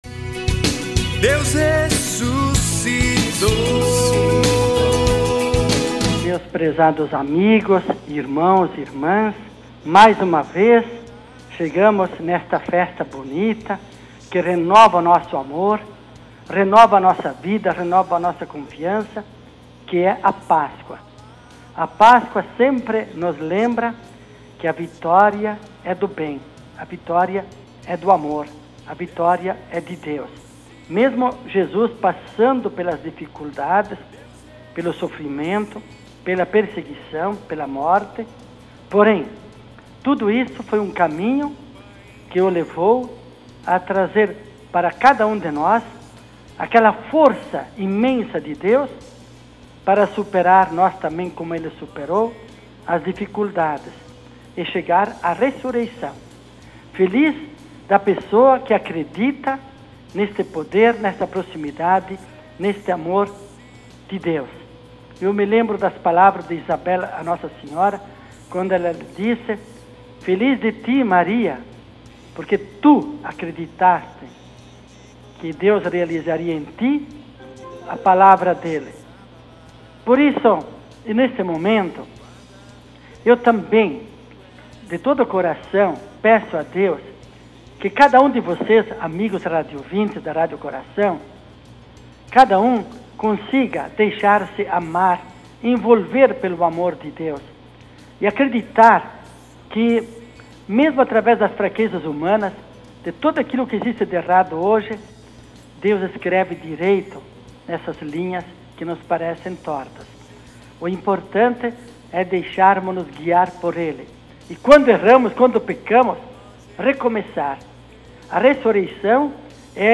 Ouça na íntegra a Mensagem do bispo para a páscoa 2015